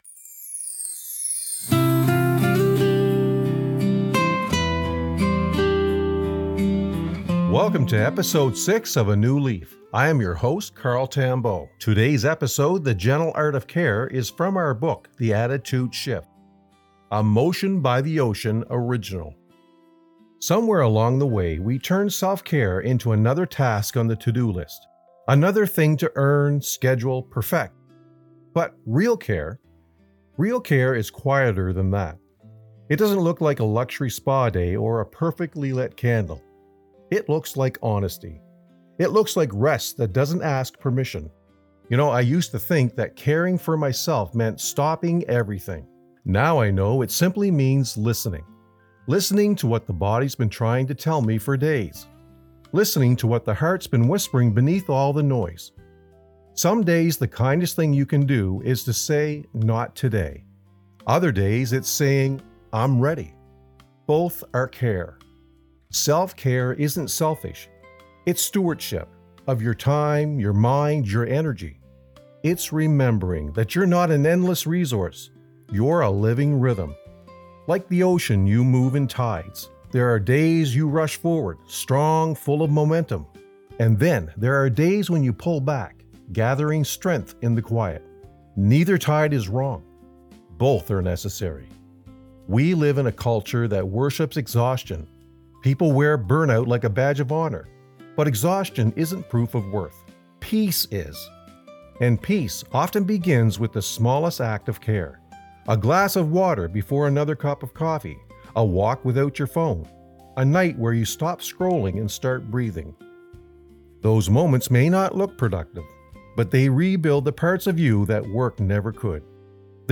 A calm, grounding reminder that caring for yourself is the first step toward carrying anything else.